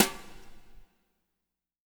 BW BRUSH03-L.wav